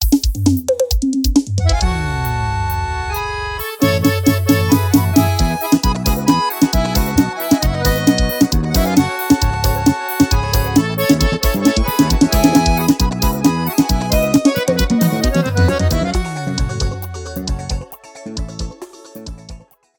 • Demonstrativo Forrozão:
• São todos gravados em Estúdio Profissional, Qualidade 100%